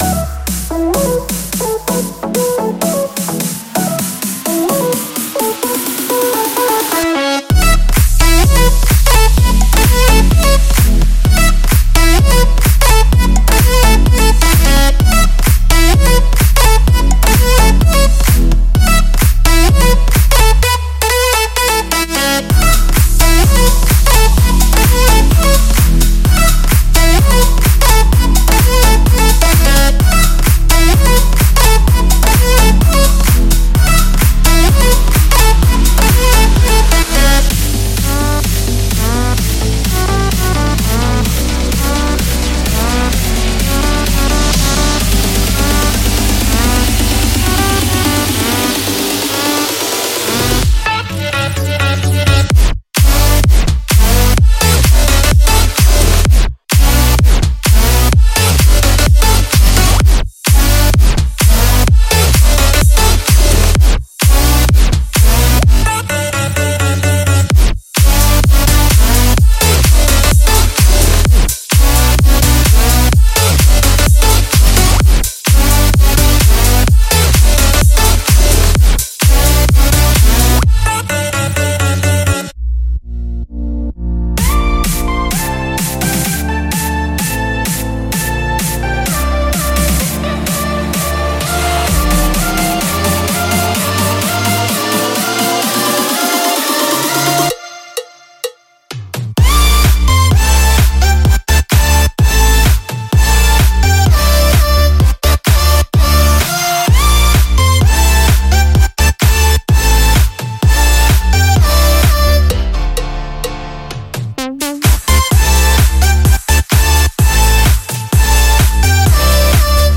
2025-12-28 Future Bass · Future House 411 推广
➜394 One Shots & Drum Loops